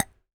metal_tiny_hit_impact_01.wav